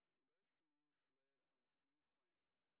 sp01_street_snr10.wav